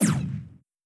Laser Shoot.wav